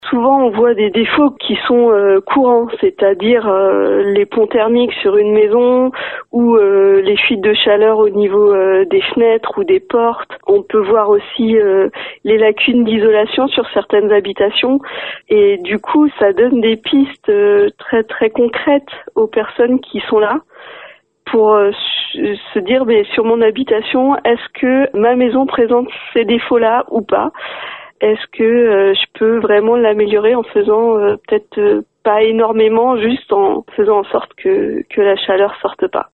On l’écoute :